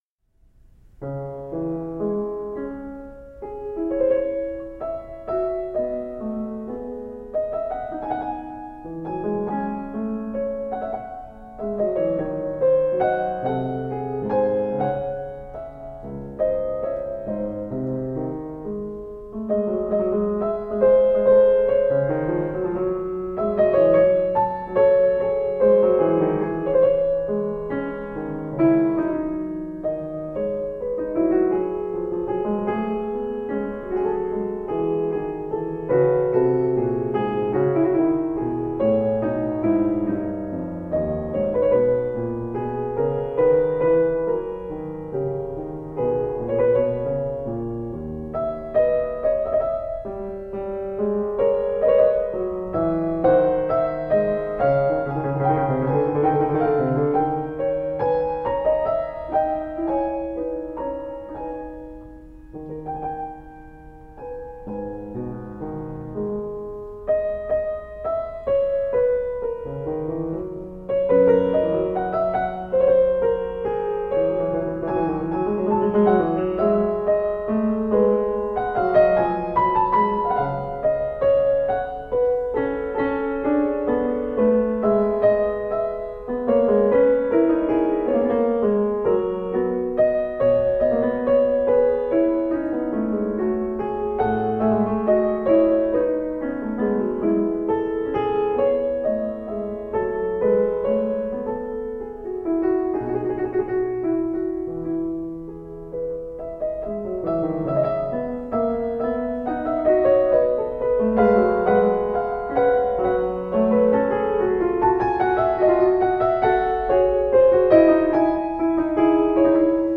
played with deep expressiveness and intelligence
solo piano music